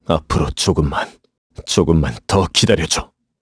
Clause_ice-Vox_Victory_kr.wav